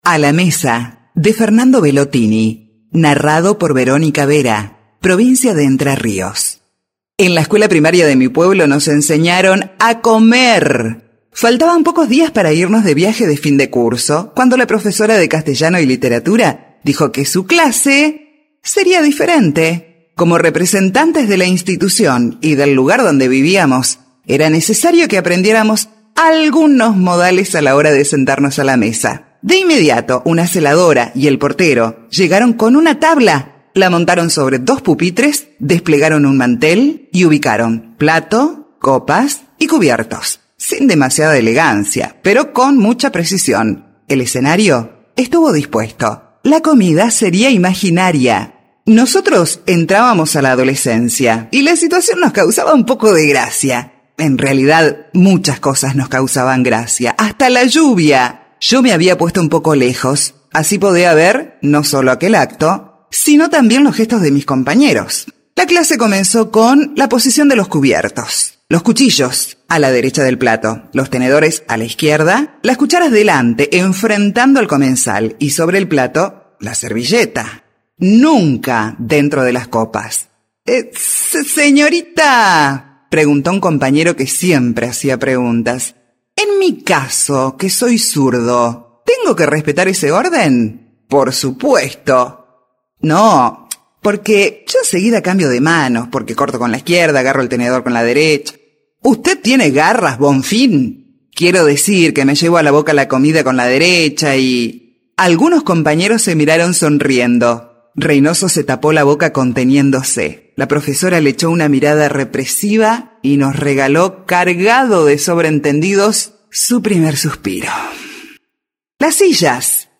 Incluido en la Audioteca Federal del CFI por la Provincia de Entre Ríos
CFI - Audiocuento -  ENTRE RIOS.mp3